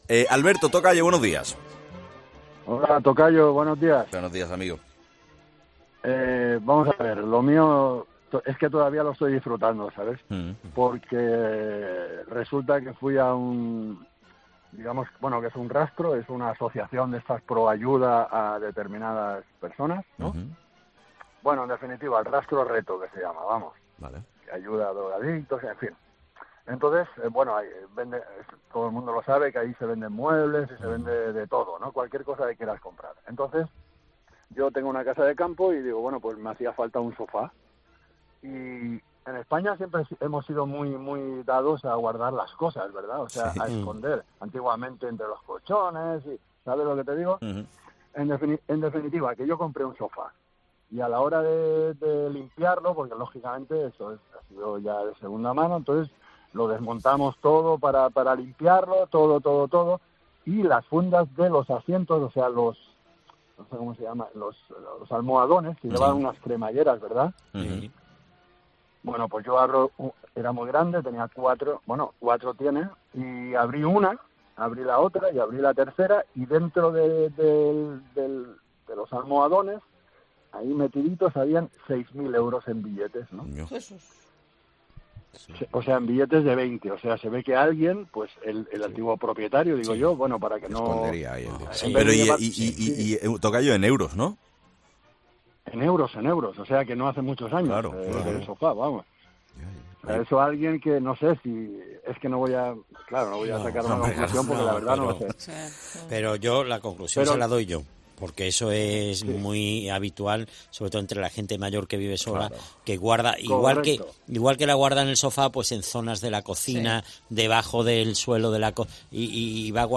Cuentan los 'fósforos' de 'Herrera en COPE' los tesoros que han encontrado y lo que han hecho con ellos